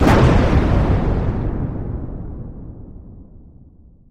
barrelExplosion.wav